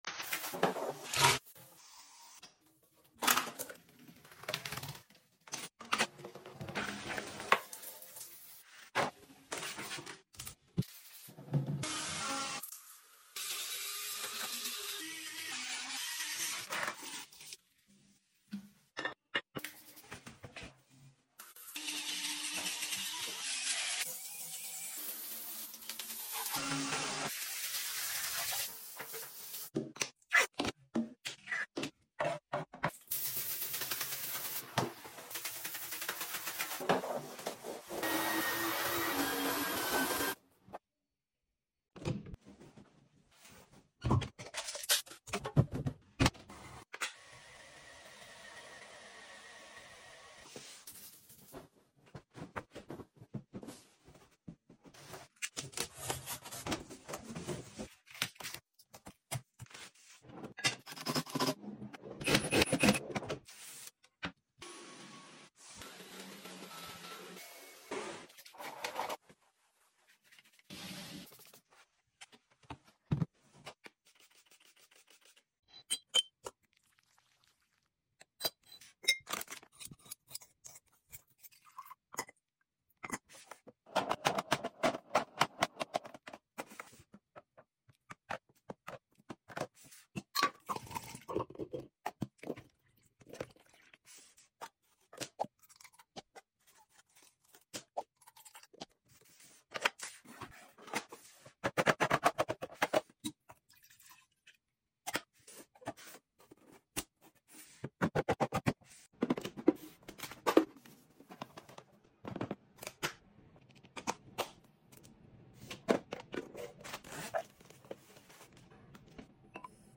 Cleaning,organizing and restocking asmr and sound effects free download